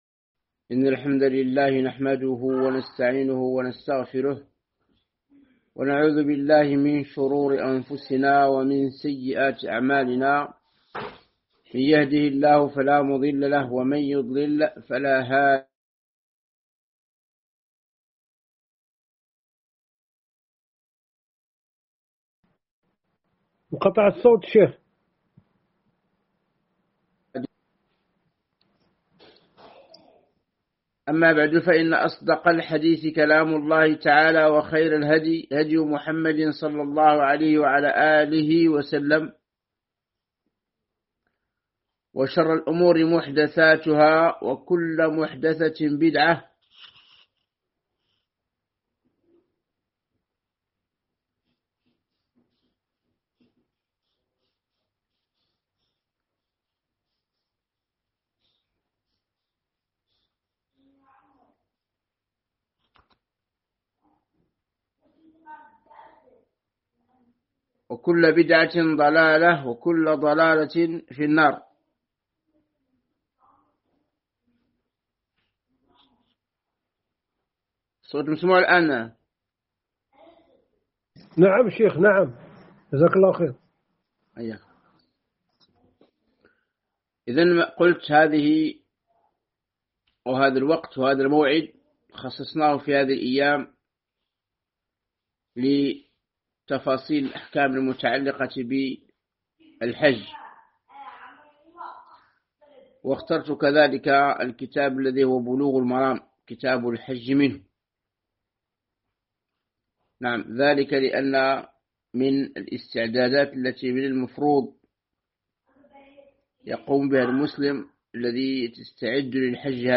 شرح بلوغ المرام من أدلة الأحكام الدرس 3